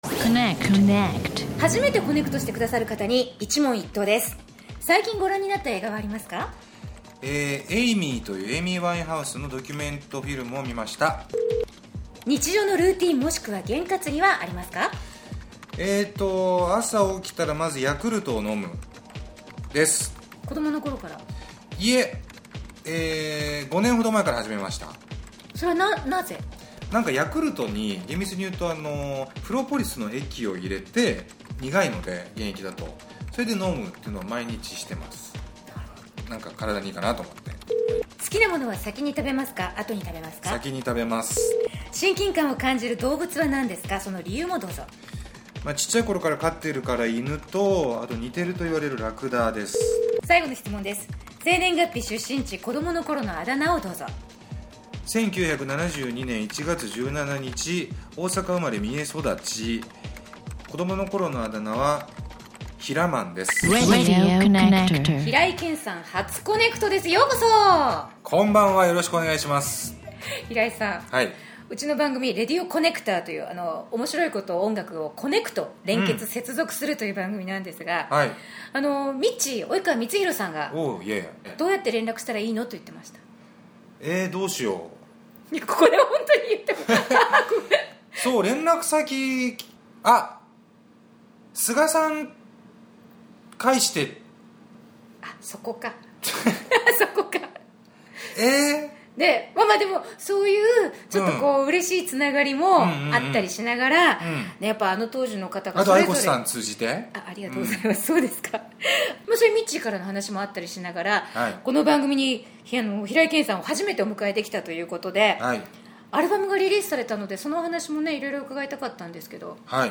ハードスケジュールの中イベント終了後の取材。
独特のテンポで真摯に語って下さっています。